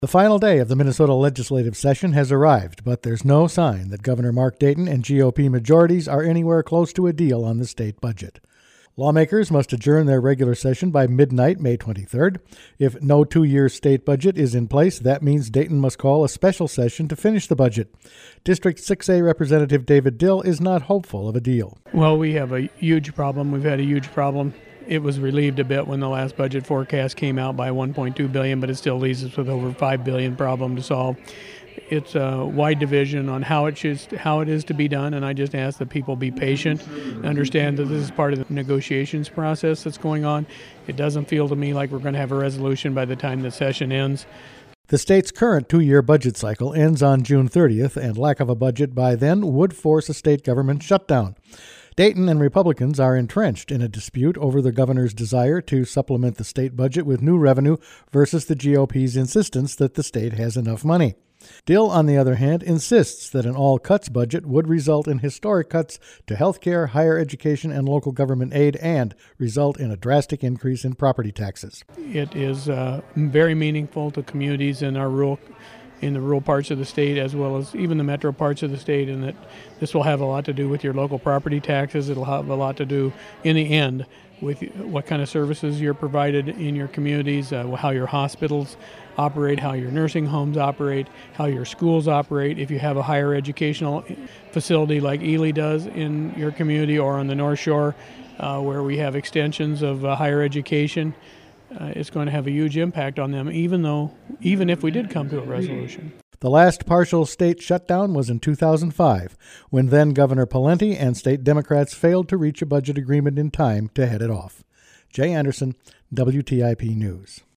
No budget in sight on last day of session | WTIP North Shore Community Radio, Cook County, Minnesota